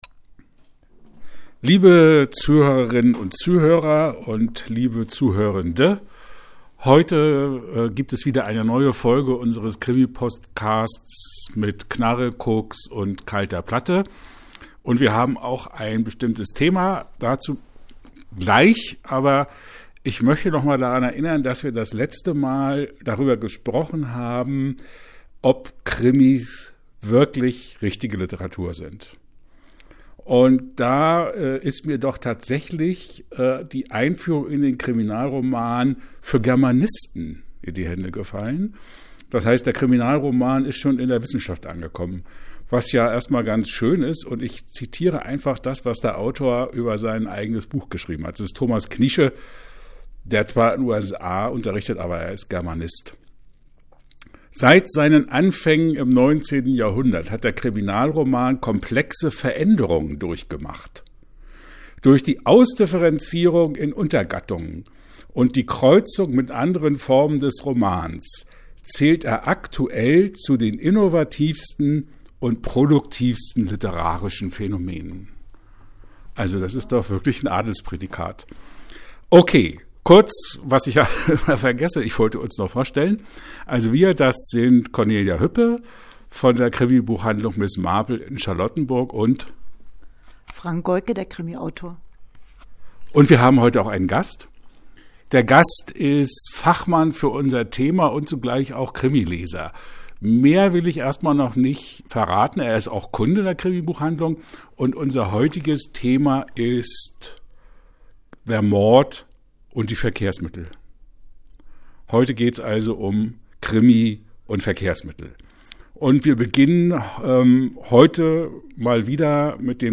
Eine Anmerkung in eigener Sache: Da wir den Podcast in der Krimibuchhandlung aufnehmen, gibt es manchmal Unterbrechungen, weil Kunden kommen (zum Glück!!!).